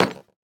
Minecraft Version Minecraft Version latest Latest Release | Latest Snapshot latest / assets / minecraft / sounds / block / nether_bricks / step3.ogg Compare With Compare With Latest Release | Latest Snapshot
step3.ogg